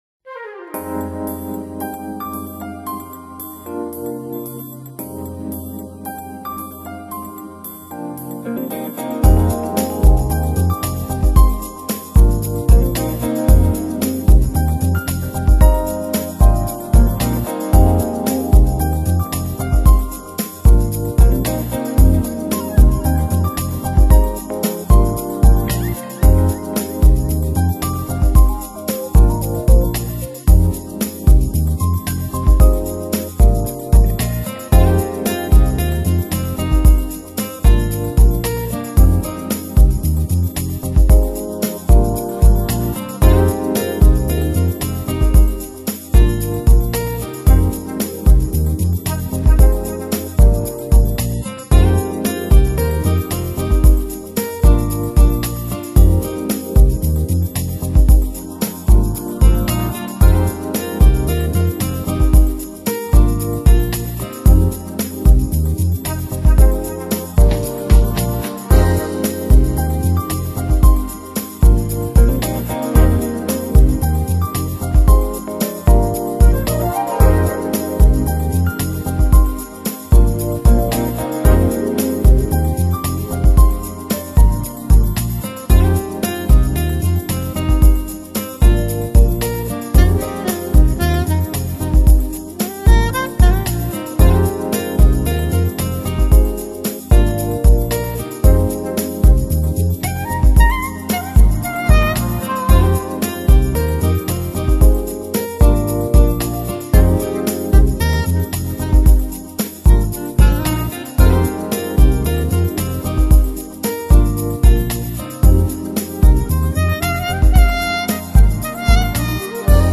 Genre..........: Smooth Jazz Lounge
QUALiTY........: VBRkbps / 44.1kHz / Joint-Stereo